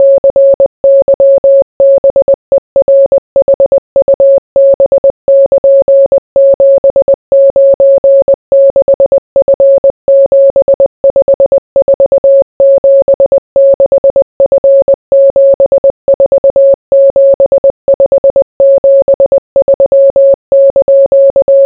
morse.wav